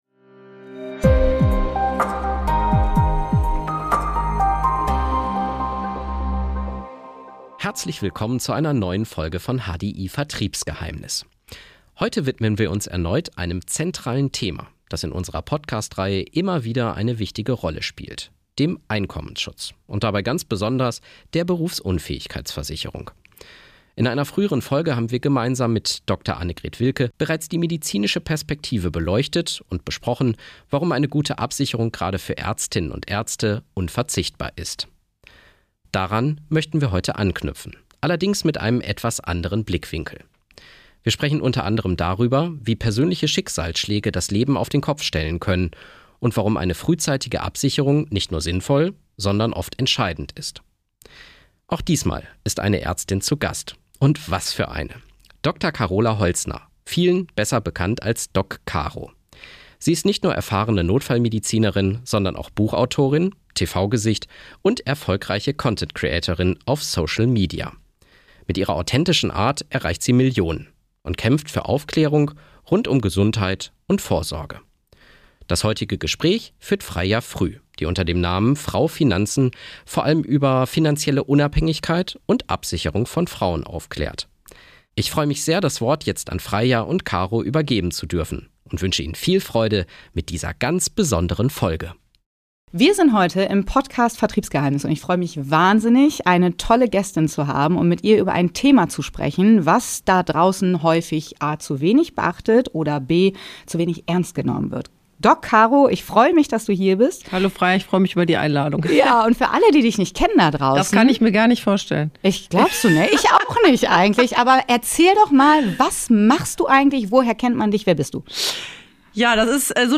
Offen und eindrücklich berichtet sie von ihren Erfahrungen, ordnet das Thema aus medizinischer Sicht ein und erklärt, warum es so wichtig ist, sich rechtzeitig mit dem Thema auseinanderzusetzen. Ein Gespräch, das zum Handeln motiviert.